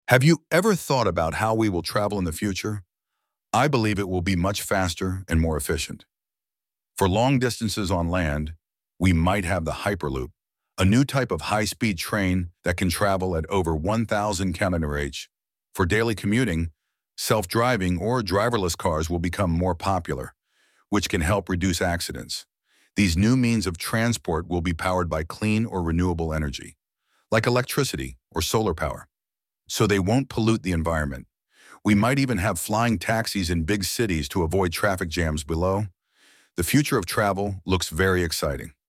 II. You will hear someone talking about transportation in the future. Listen and fill in each blank with ONE word or a number.